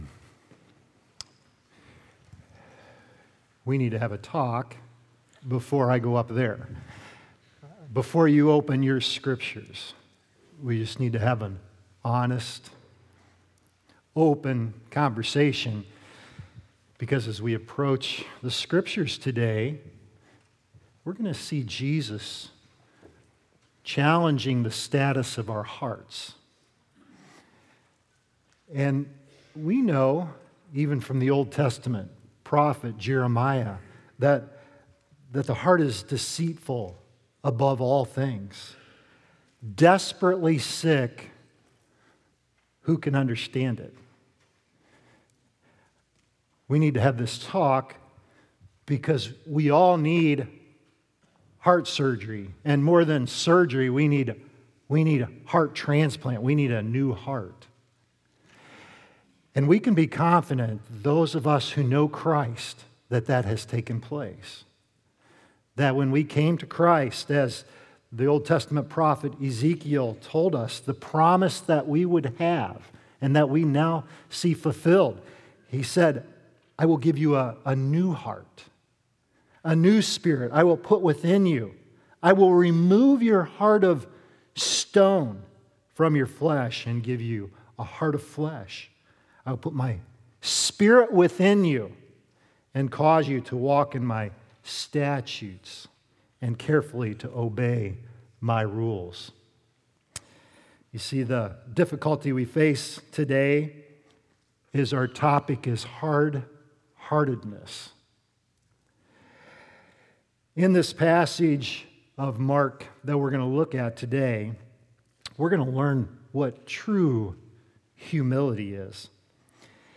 True Humility | Baptist Church in Jamestown, Ohio, dedicated to a spirit of unity, prayer, and spiritual growth